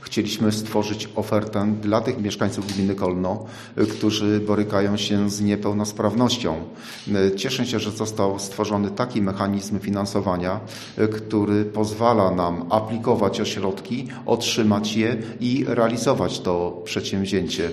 O utworzeniu miejsca dla potrzebujących mówił Wójt Gminy Kolno, Józef Bogdan Wiśniewski: